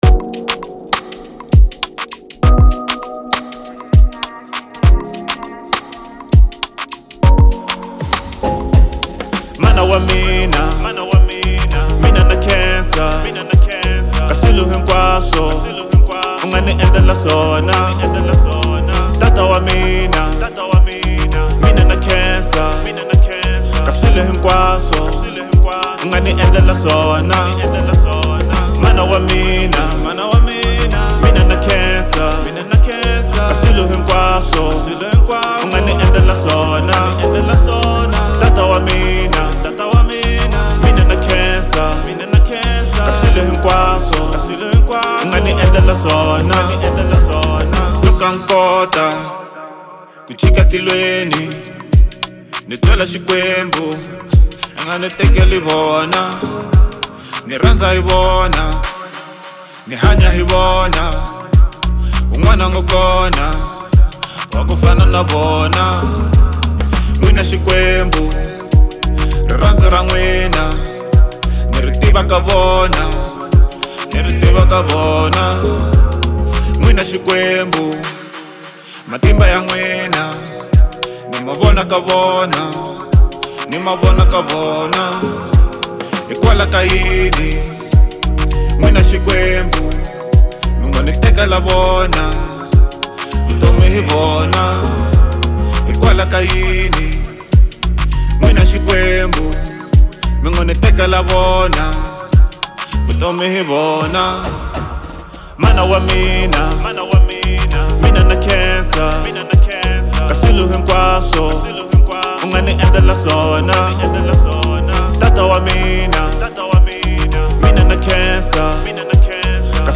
04:01 Genre : Hip Hop Size